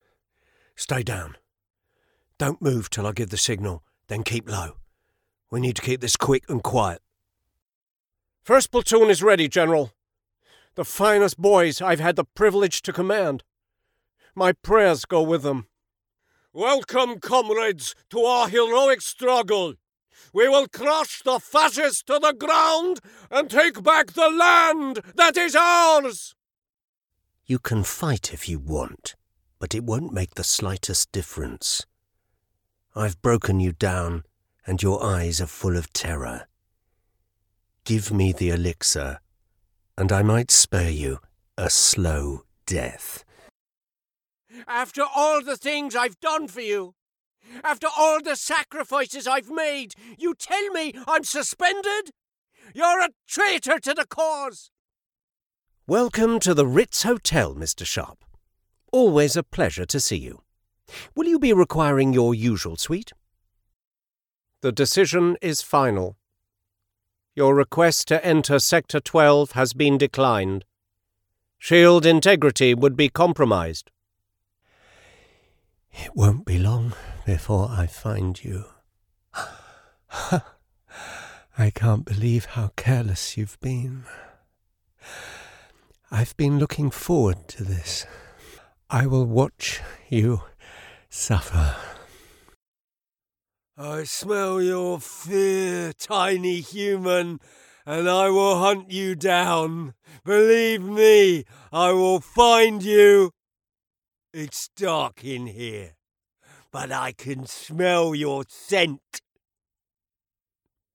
Playing age: 30 - 40s, 40 - 50s, 50 - 60s, 60+Native Accent: RPOther Accents: American, Estuary, Irish, London, Neutral, Northern, RP, Scottish, West Country, Yorkshire
• Native Accent: RP
• Home Studio